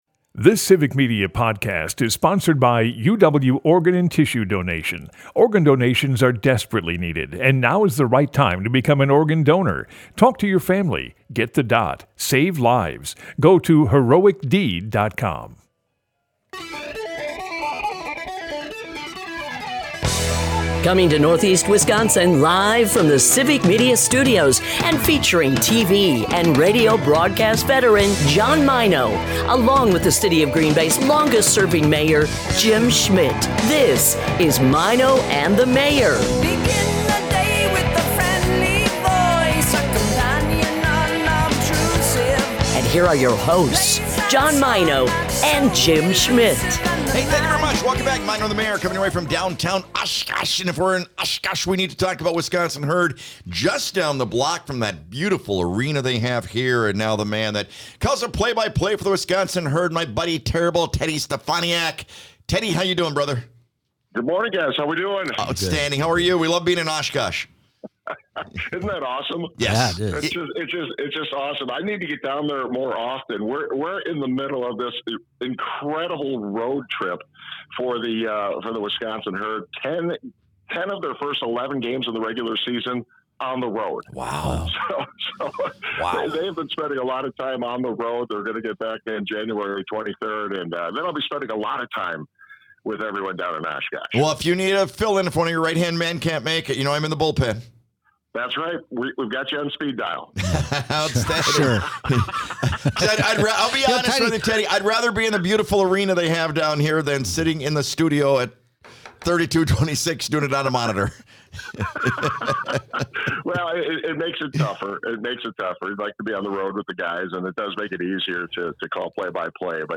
Broadcasts live 6 - 9am in Oshkosh, Appleton, Green Bay and surrounding areas.
Our show from our Oshkosh studio continues